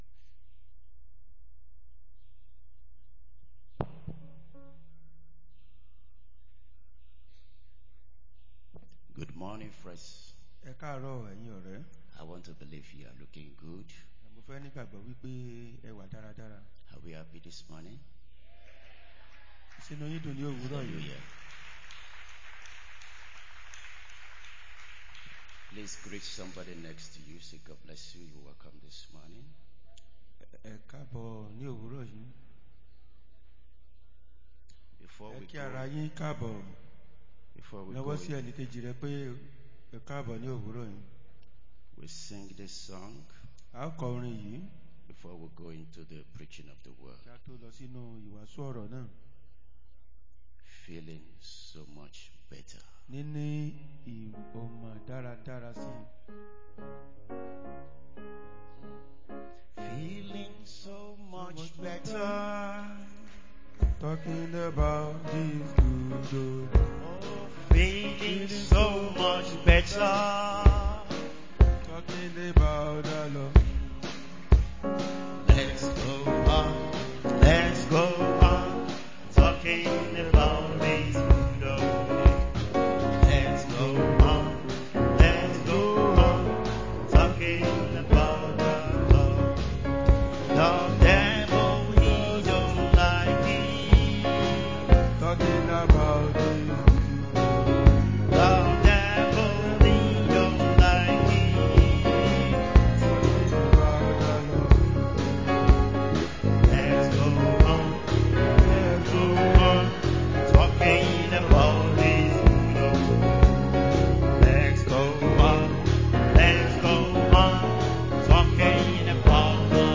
2025 Believers' Convention
Morning Devotion